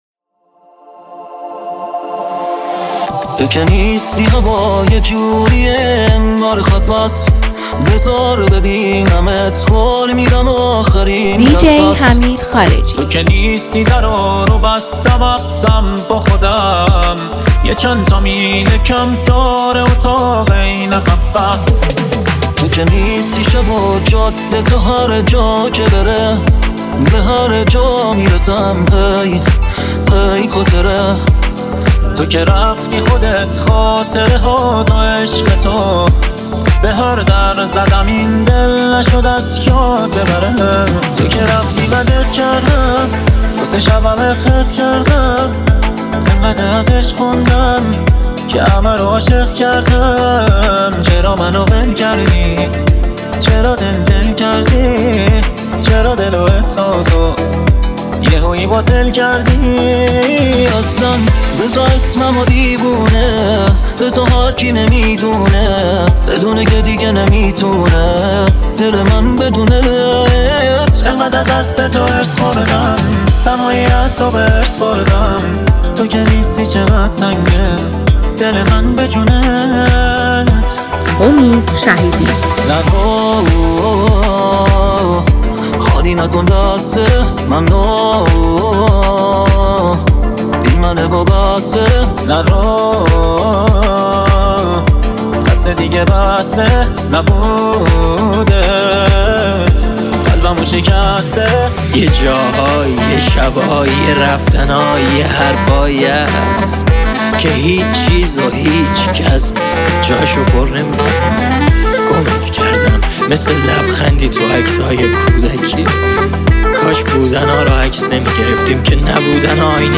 ریمیکس هوش مصنوعی